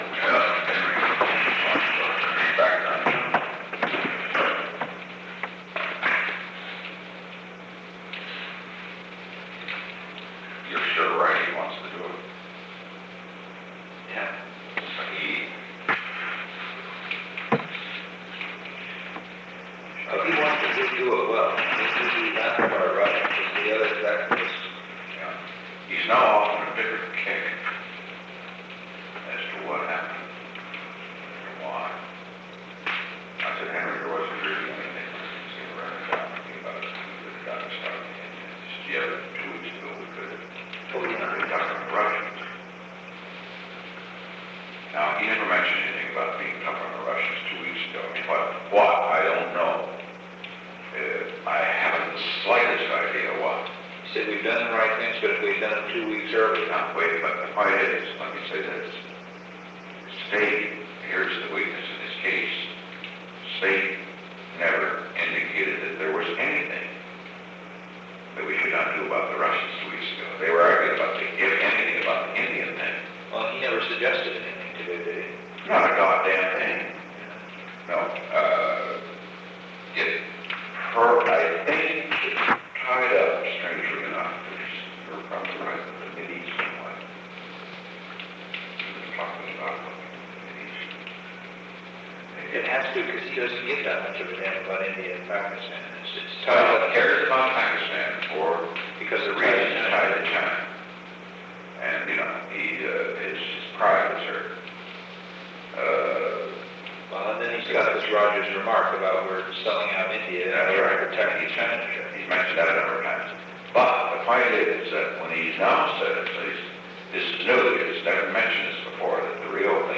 Participants: Nixon, Richard M. (President) ; Haldeman, H. R. ("Bob") ; Butterfield, Alexander P. Recording Device: Oval Office
The Oval Office taping system captured this recording, which is known as Conversation 631-007 of the White House Tapes.